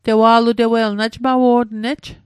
tewa'lutewei :: dandelion -- Mi'gmaq/Mi'kmaq Online